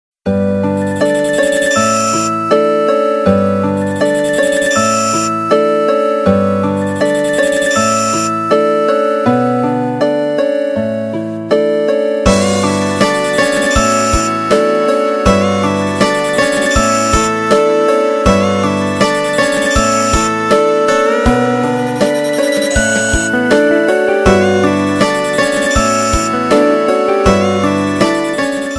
Catégorie Telephone